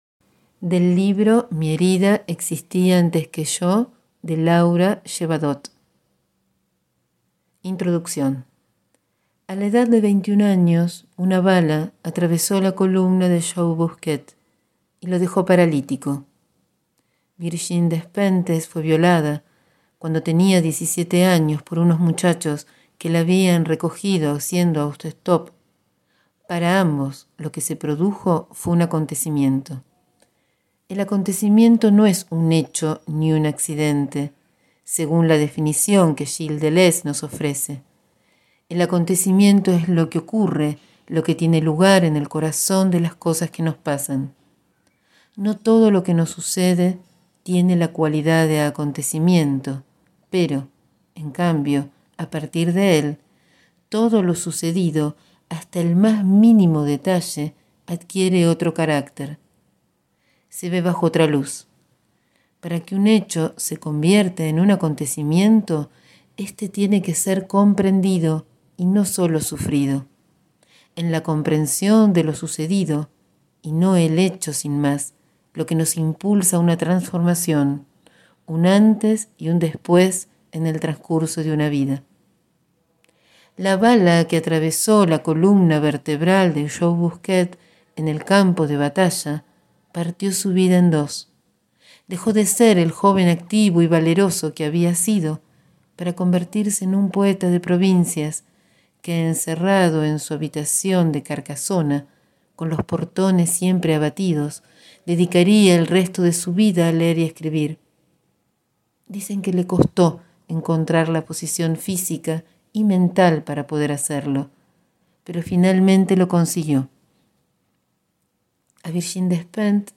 Leo los primeros párrafos de la introducción del libro «Mi herida existía antes que yo» de Laura Llevadot.